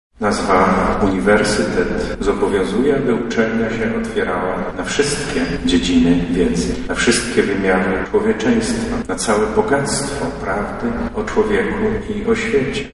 Oprawę muzyczną nabożeństwa przygotowały chóry akademickie UMCS, KUL, Uniwersytetu Medycznego i Przyrodniczego, a homilię wygłosił Metropolita Lubelski, Stanisław Budzik.